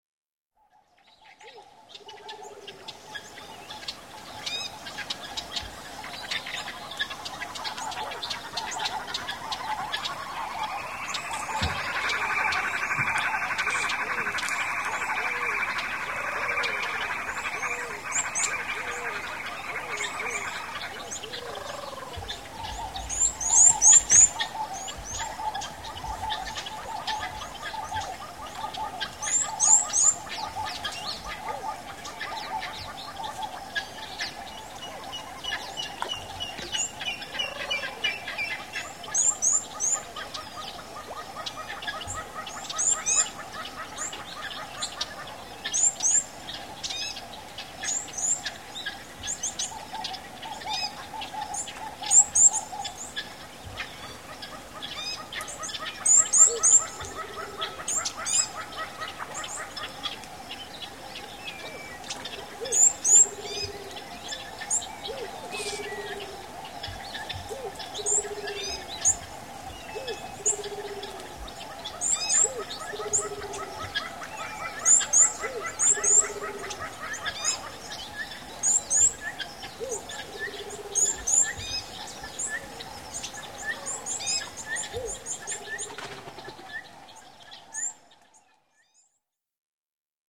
labyrinthe des sons.
sonotheque__cd1____50___jungle_africaine___radio_france.mp3